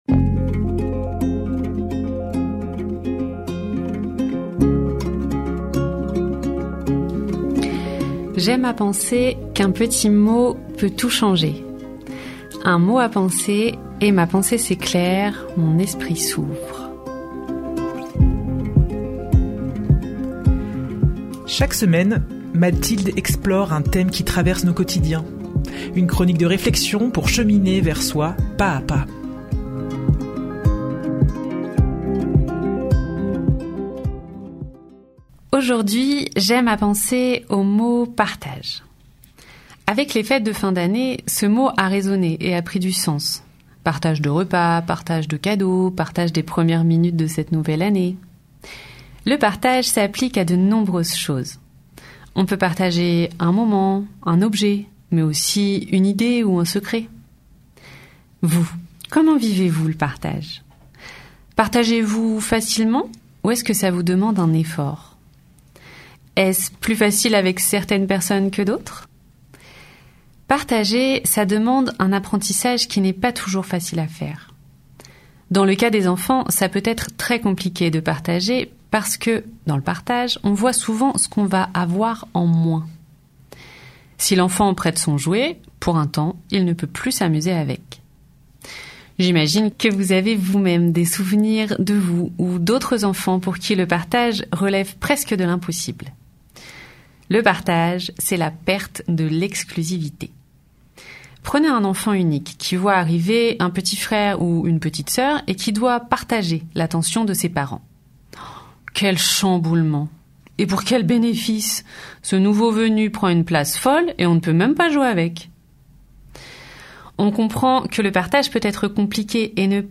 Une nouvelle chronique de réflexion pour cheminer vers soi pas-à-pas.